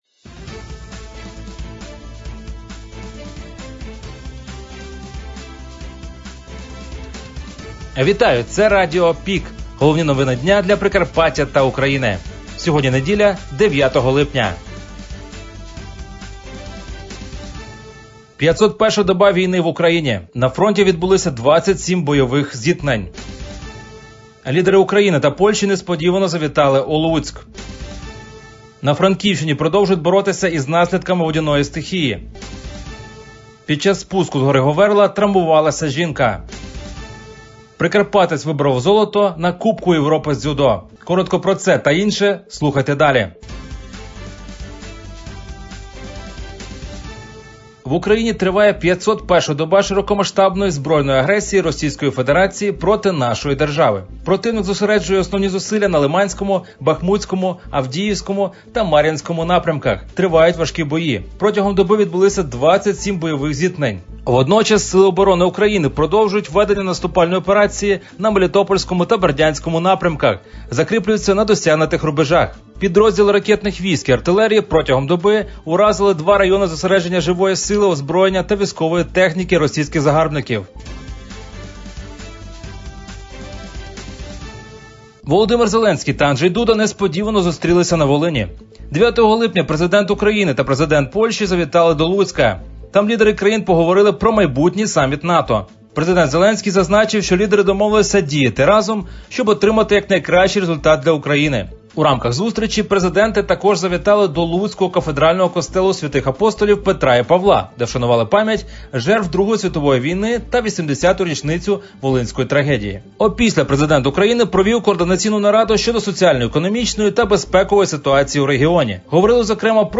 Пропонуємо вам актуальне за день - у радіоформаті.